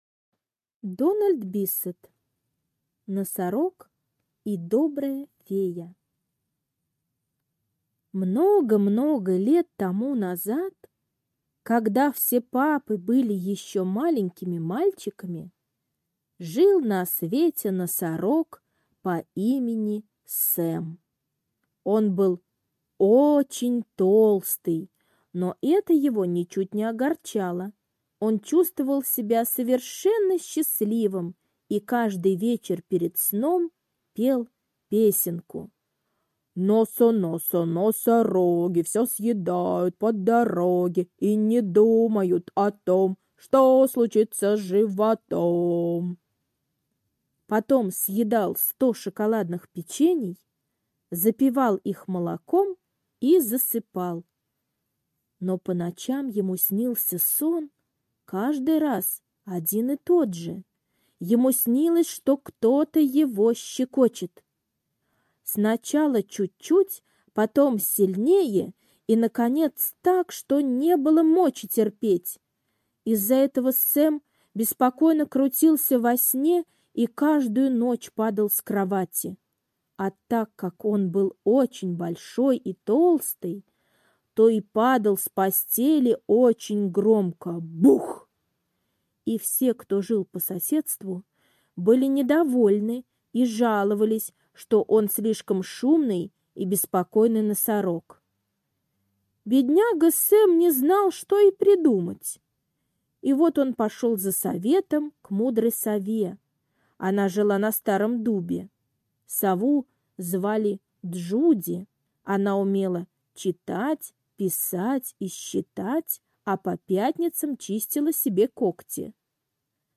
Носорог и добрая фея - аудиосказка Биссета Д. Сказка про то, как фея помогла носорогу, который вечно падал с кровати во сне…